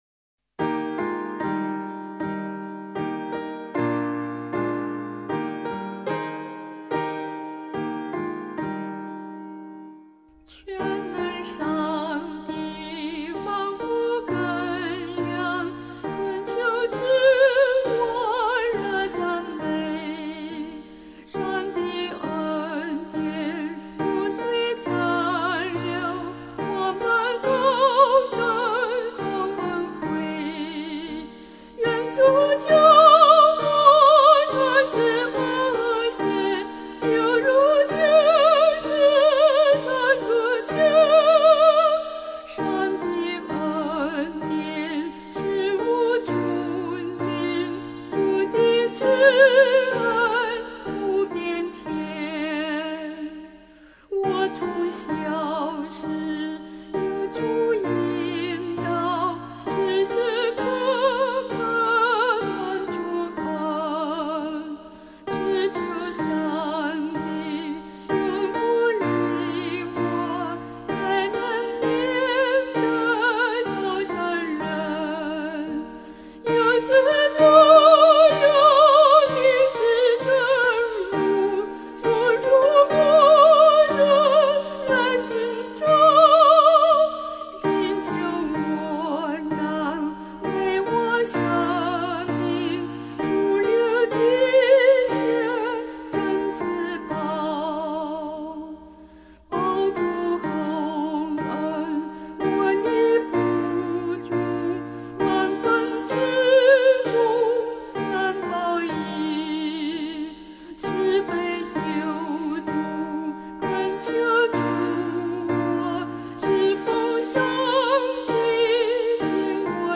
伴奏
这首诗的曲调有很强烈的民族风味，全曲只有两句。